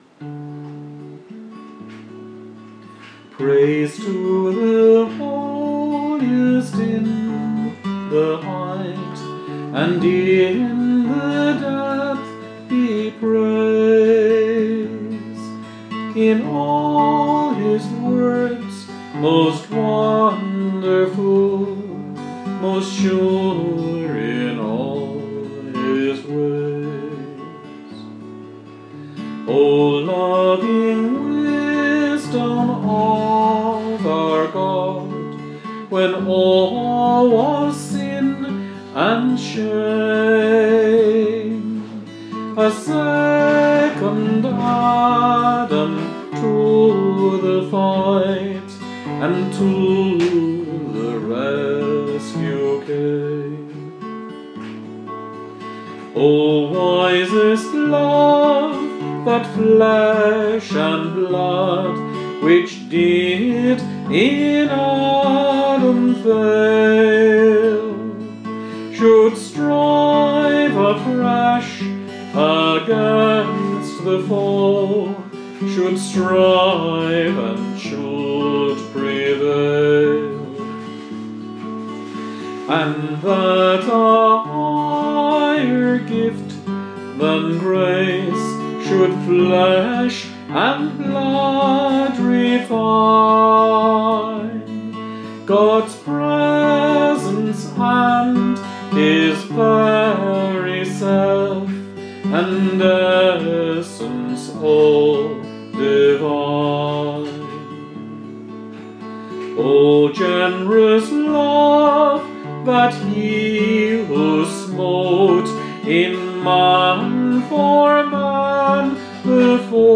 Hymn easter